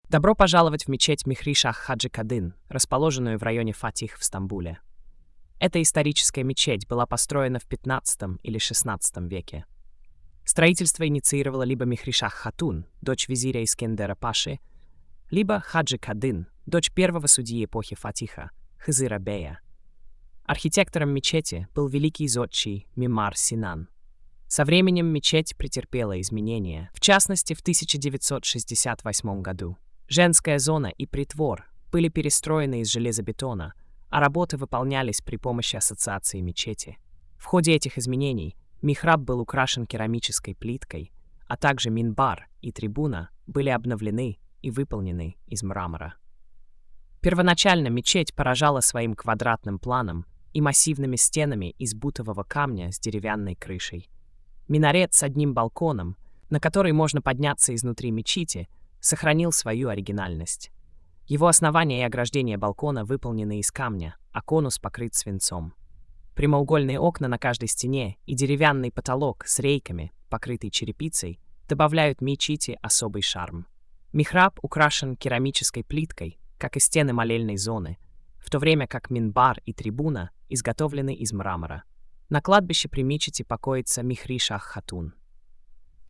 Аудиоповествование'